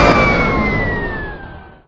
jet-stop.ogg